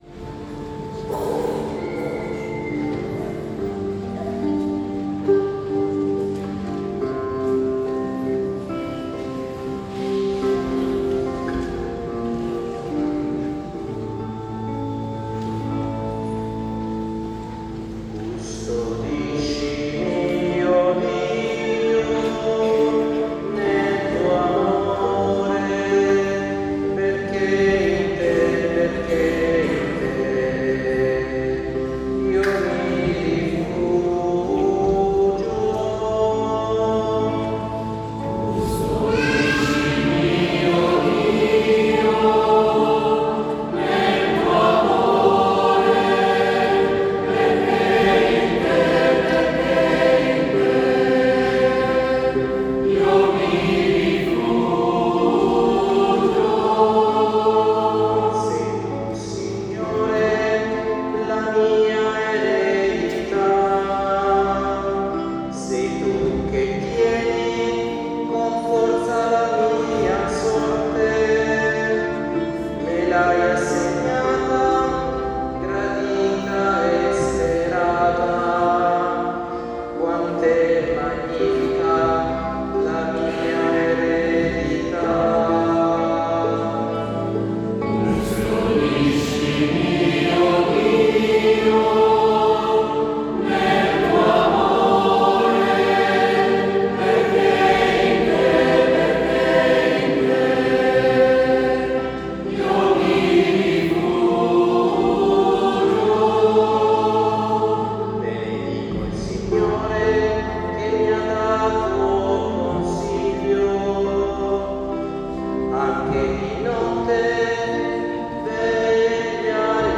19 aprile 2025 - Notte di Pasqua
Organo
Chitarra
Cimbaletti
Bonghi